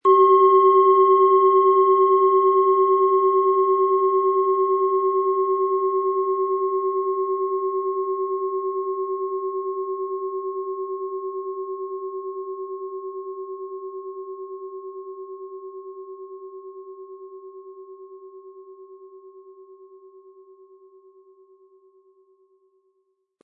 Planetenton 1
HerstellungIn Handarbeit getrieben
MaterialBronze